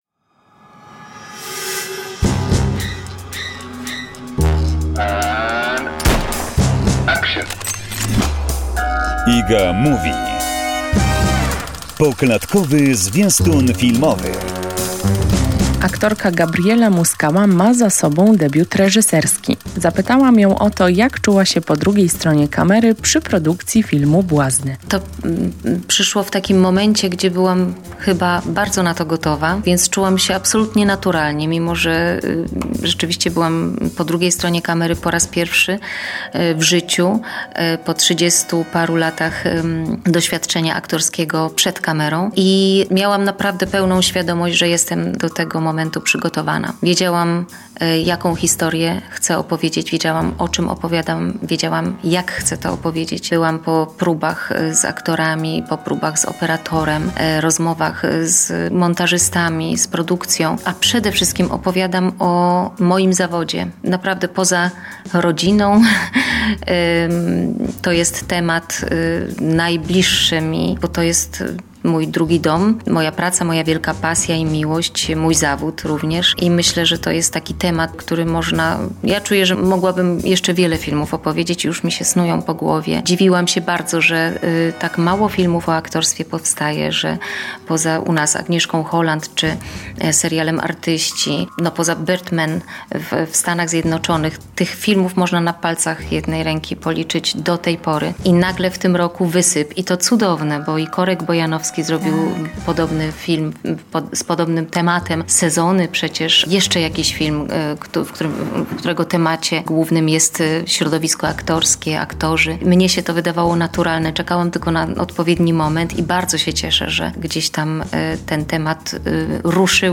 rozmowa z Gabrielą Muskałą